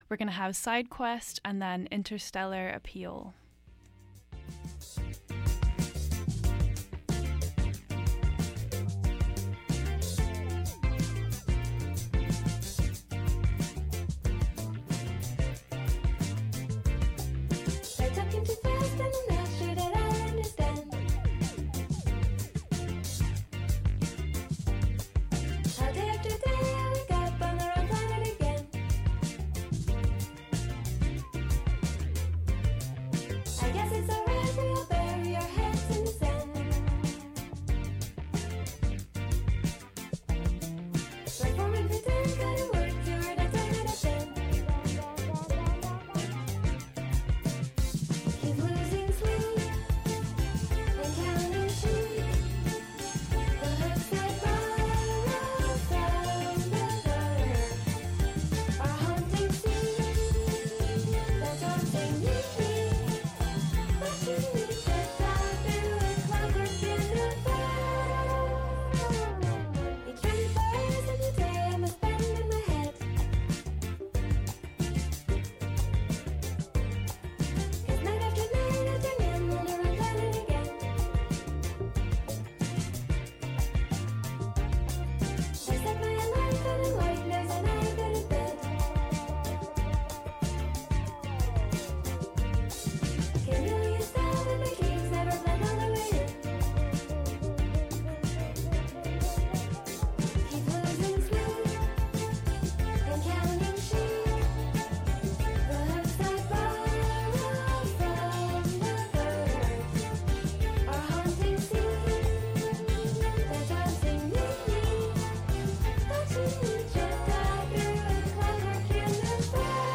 This episode features a very special interview I had with Pearl & The Oysters here in Vancouver a few days ago! We recorded this interview tucked up inside the attic of The Pearl on Granville Street.